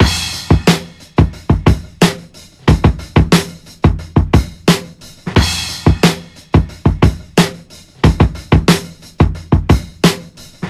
• 90 Bpm Breakbeat Sample F Key.wav
Free drum beat - kick tuned to the F note. Loudest frequency: 1007Hz
90-bpm-breakbeat-sample-f-key-H4t.wav